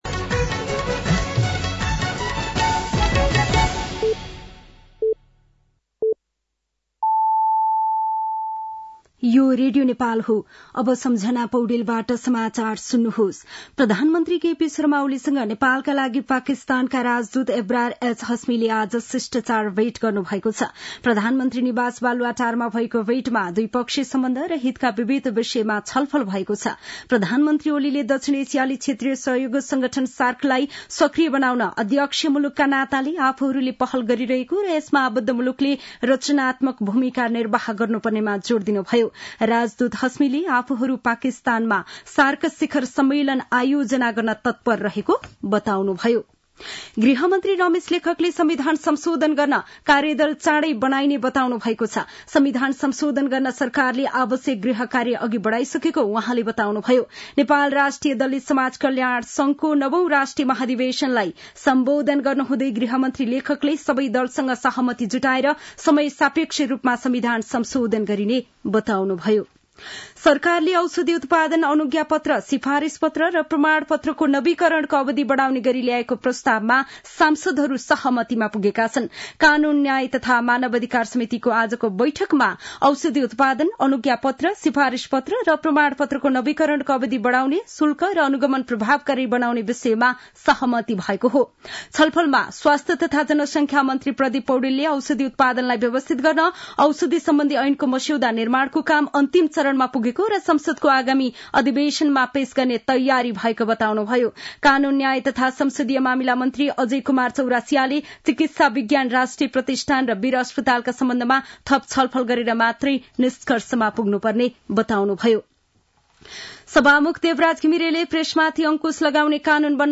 साँझ ५ बजेको नेपाली समाचार : १० पुष , २०८१
5-pm-nepali-news-9-09.mp3